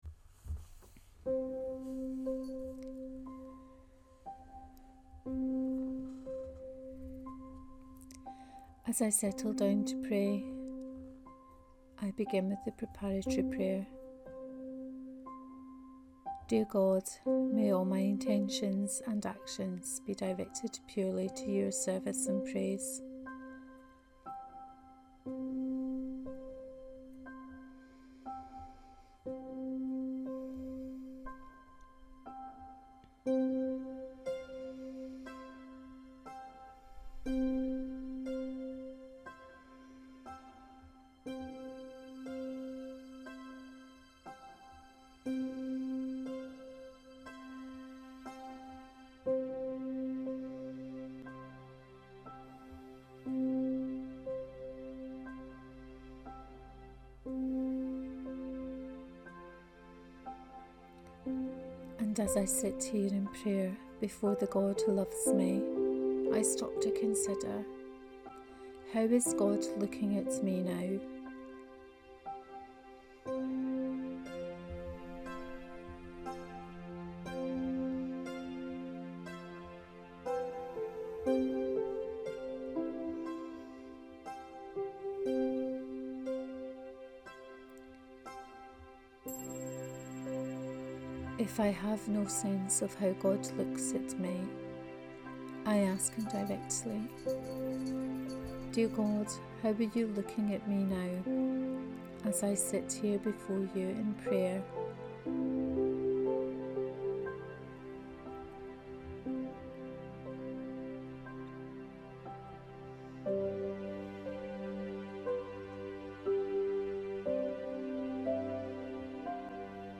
Imaginative Contemplation for the Feast of Pentecost. Guided prayer.